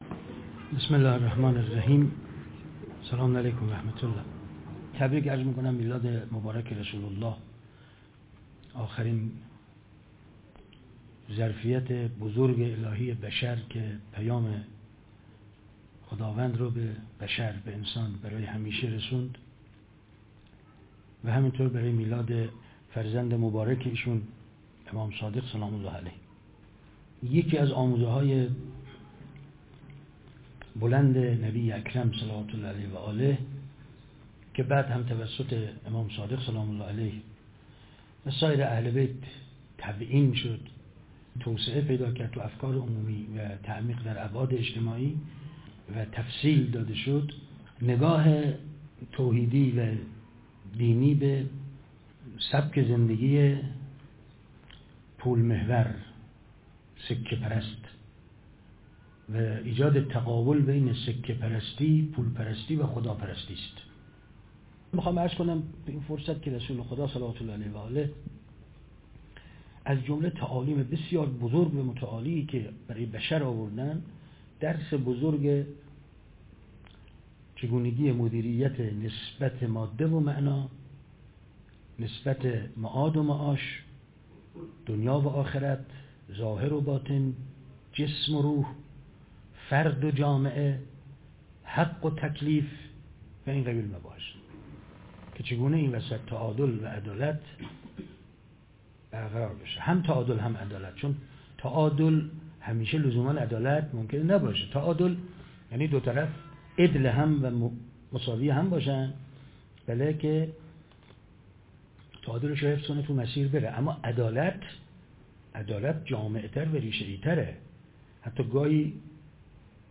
میلاد پیامبر اکرم (ص) و امام صادق (ع) - مشهد - نشست 'مسلمان محمدی، شیعه جعفری)